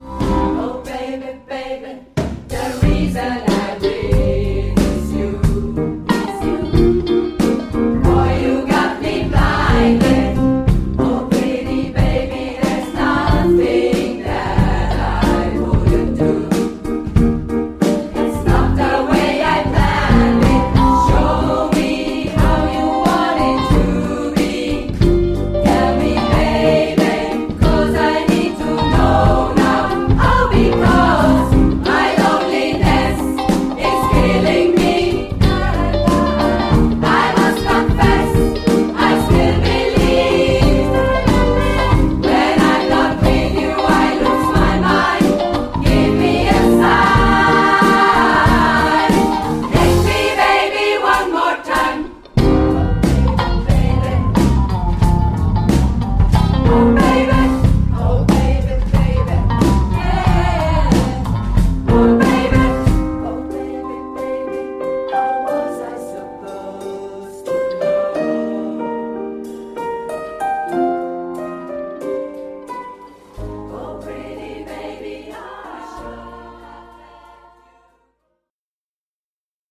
Band
SSAA